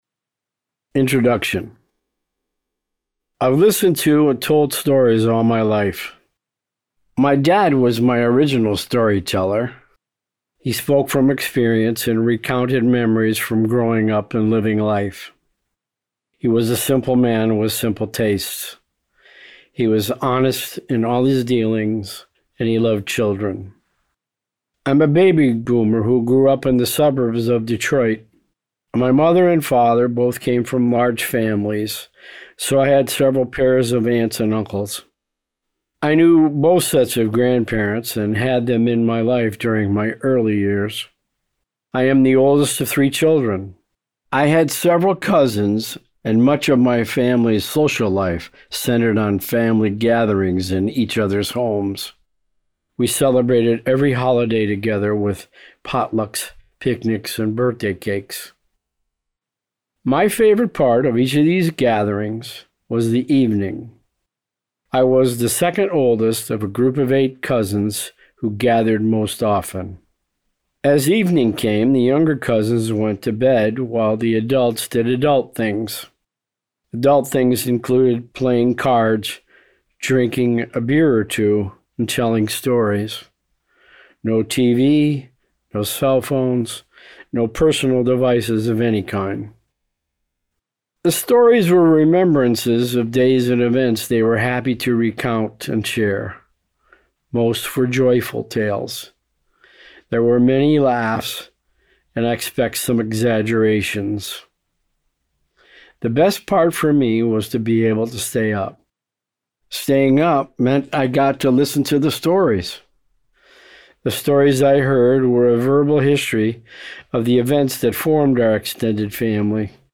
I’ve spent the past several weeks recording an audio version of my book.